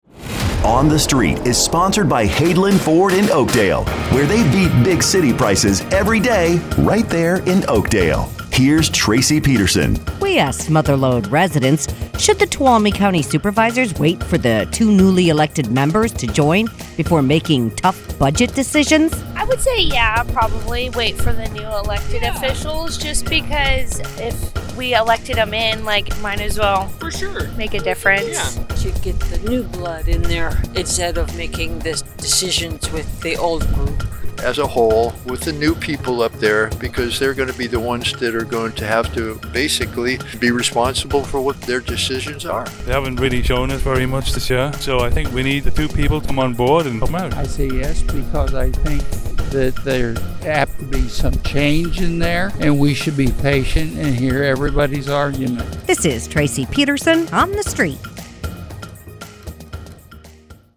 asks Mother Lode residents, “Should the Tuolumne County Supervisors wait for the two newly elected members to join before making tough budget decisions?”